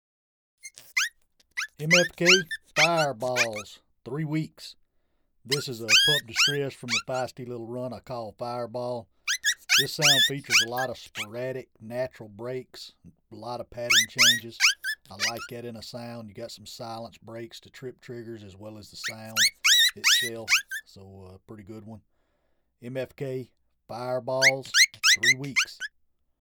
Recorded with the best professional grade audio equipment MFK strives to produce the highest
The Big Difference- Our one-of-a-kind live coyote library naturally recorded at extremely close
range from our very own hand raised, free range coyotes sets MFK apart from all other libraries. Making